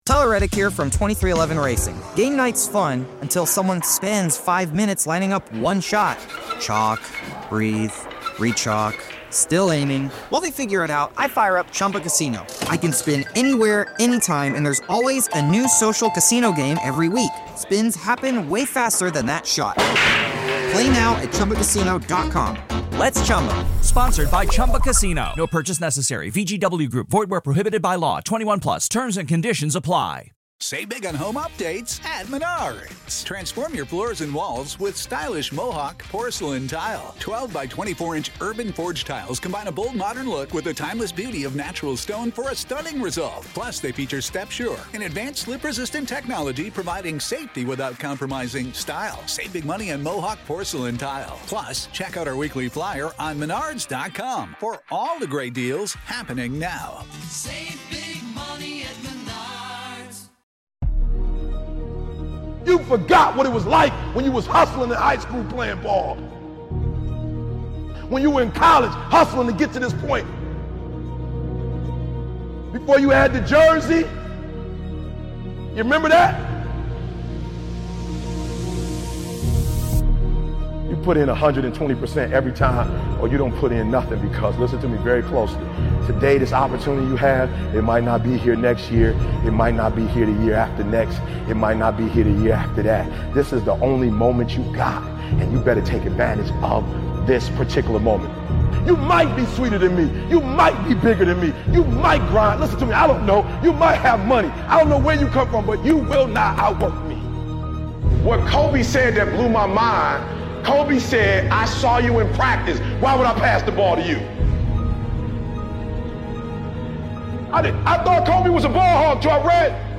It's time to become obsessed with being the hardest worker in the room. Lock in and focus on the opportunity you have today. One of the Best Motivational Speeches by Eric Thomas.